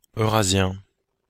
Ääntäminen
Ääntäminen Belgique (Brabant wallon): IPA: [øʁazjɛ̃] Haettu sana löytyi näillä lähdekielillä: ranska Käännös Substantiivit 1. euroasiático 2. eurasiático Suku: m . Määritelmät Substantiivit Habitant de l’ Eurasie .